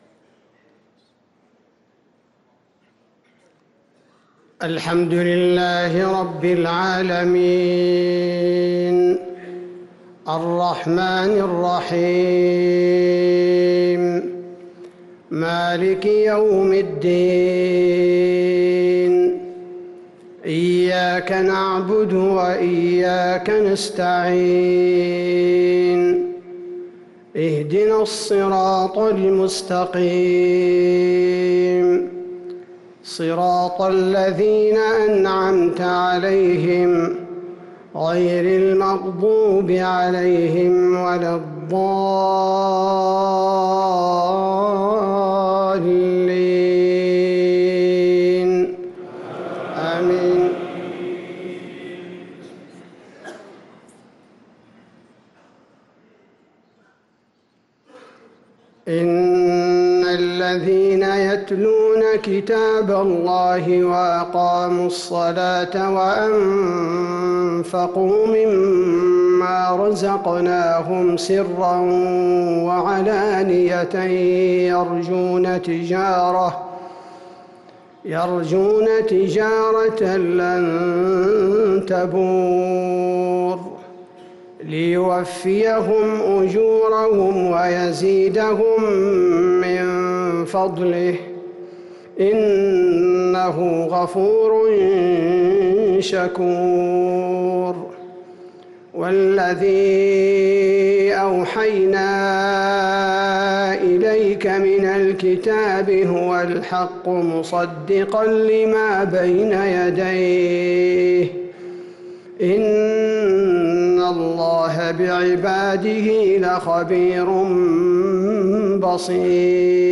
صلاة المغرب للقارئ عبدالباري الثبيتي 28 شوال 1444 هـ